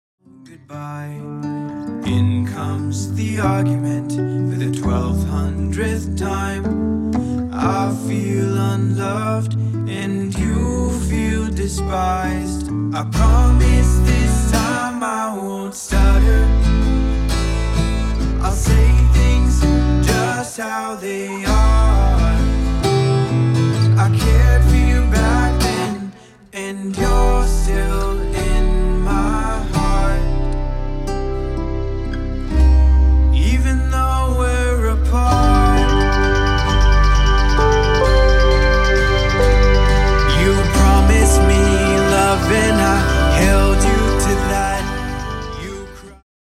Indie Acoustic
Indie-Acoustic.mp3